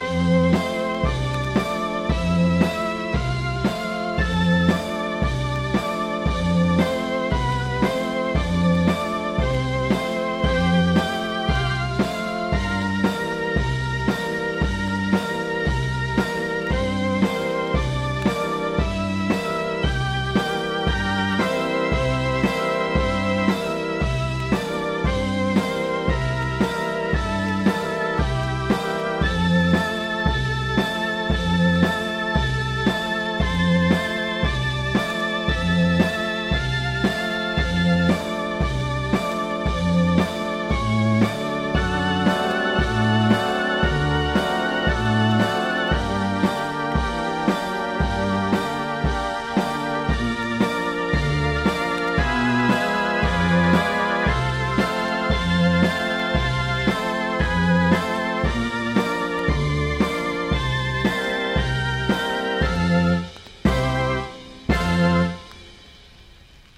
Hier in einer Fassung für Kirmesorgel:
lpa-gwidlp-kirmesorgel.mp3